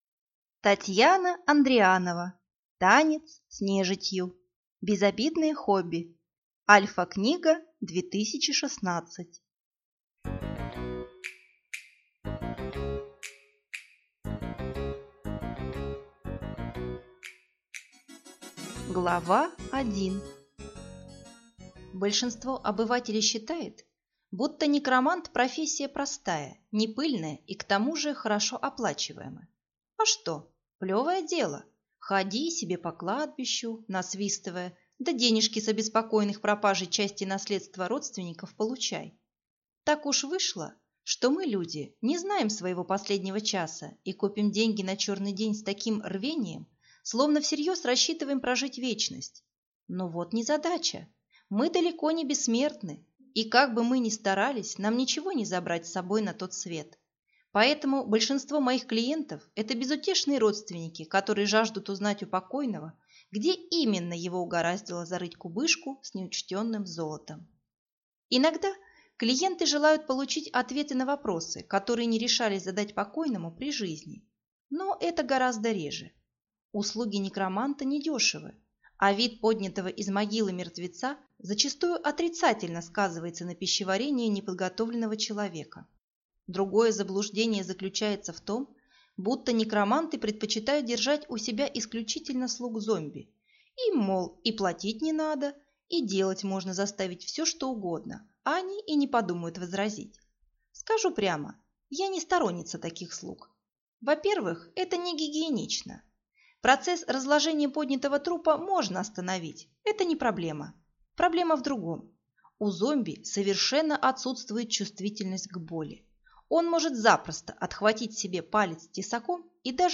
Аудиокнига Танец с нежитью | Библиотека аудиокниг